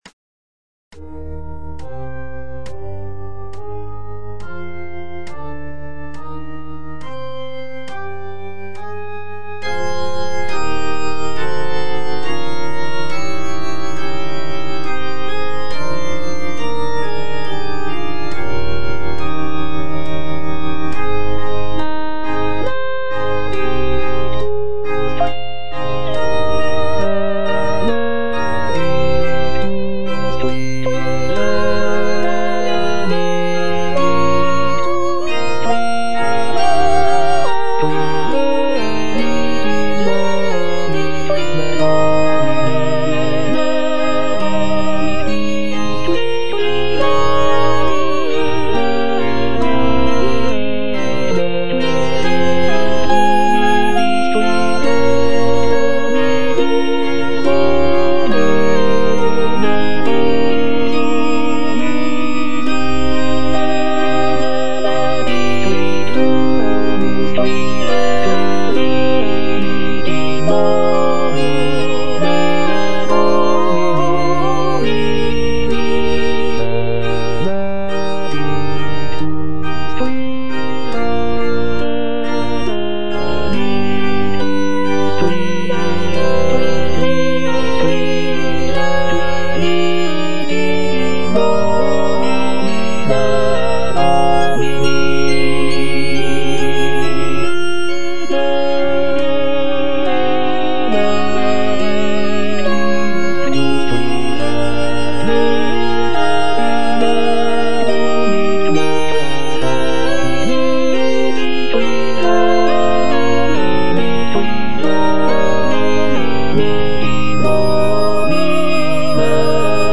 J.G. RHEINBERGER - MASS IN C OP. 169 Benedictus - Soprano (Voice with metronome) Ads stop: auto-stop
It is composed for SATB choir, soloists, organ, and orchestra.